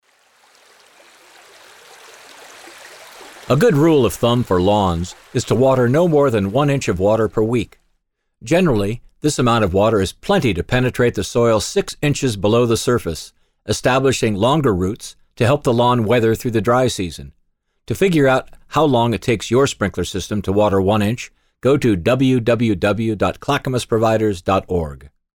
The audio PSAs (Public Service Announcements) below are designed to inform and educate our customers on ways to be more efficient with their outdoor water use throughout the summer and how to turn down and shut off outdoor watering in the late summer in time for the fall fish migration in the Clackamas River.